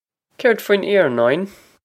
Pronunciation for how to say
Kayrd fween ee-noh-in?
This is an approximate phonetic pronunciation of the phrase.